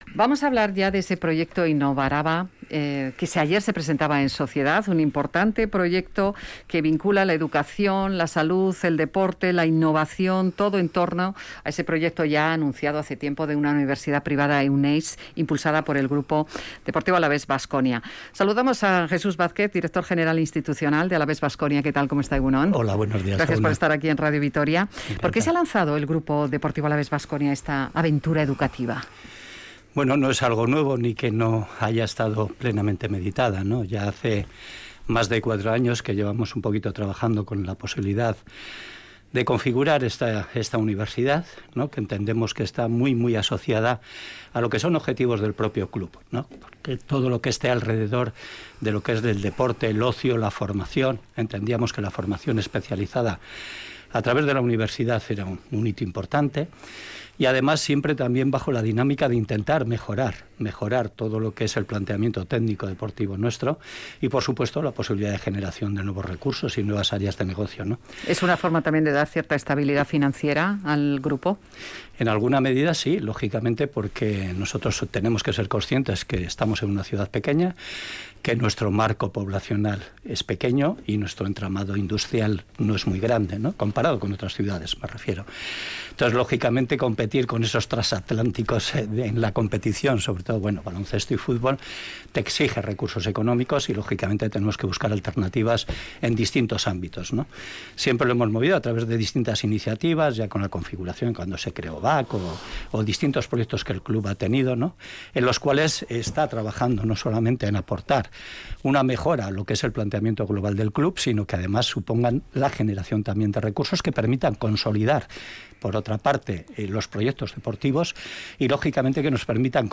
Entrevistado en Radio Vitoria